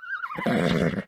animalia_horse_idle.3.ogg